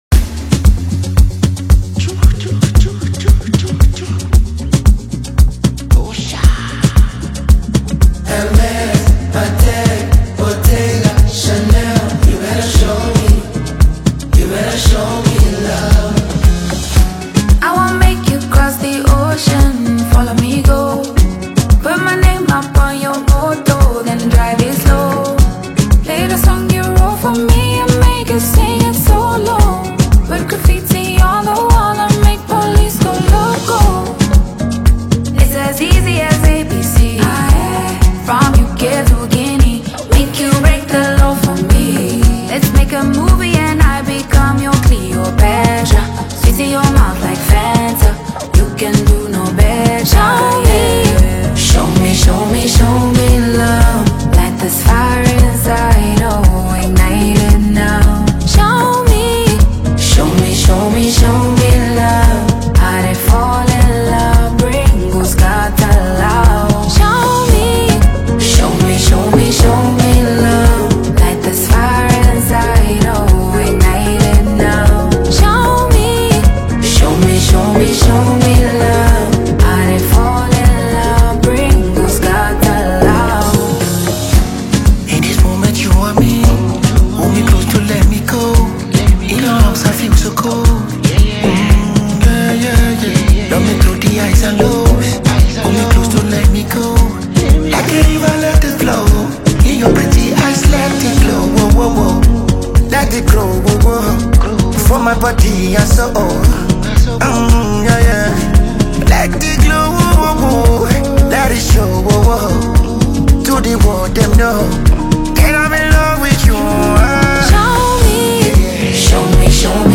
lush melodies paired with emotional depth